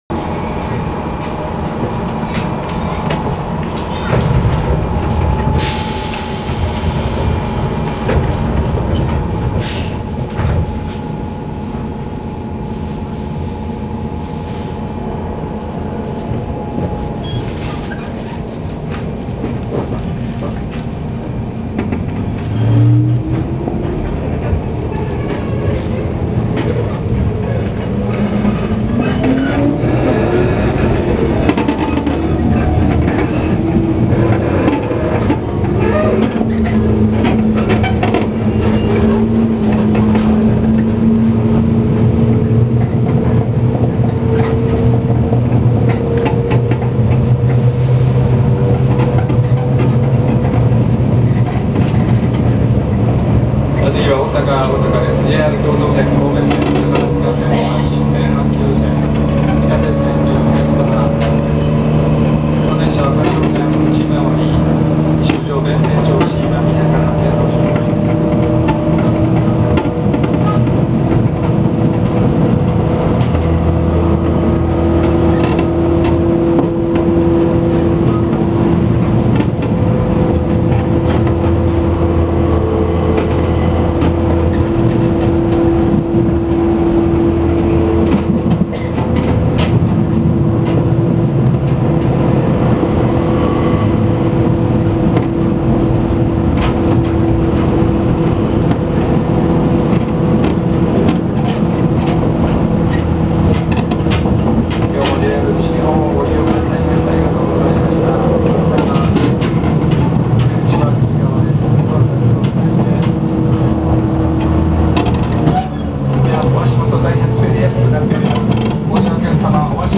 〜車両の音〜
・103系（低音モーター）走行音
【大阪環状線】天満〜大阪（2分38秒：860KB）
最も基本となる103系の走行音。